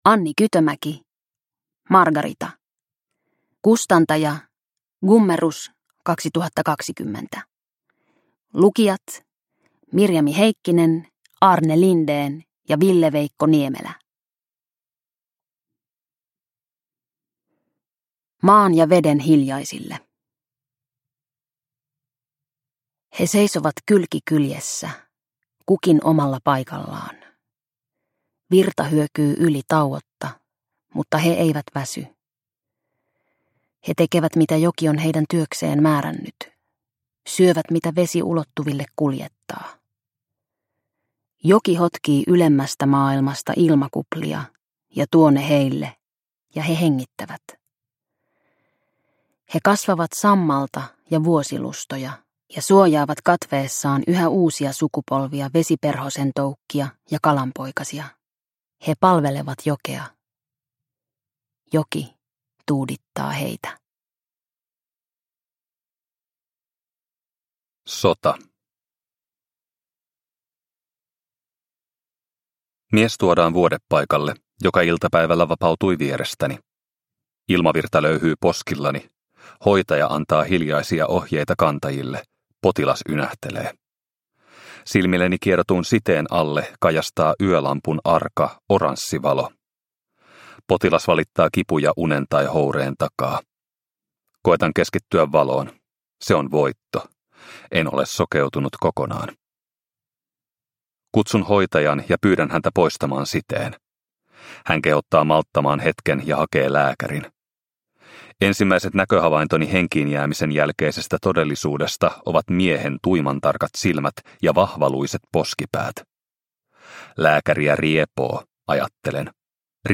Margarita – Ljudbok – Laddas ner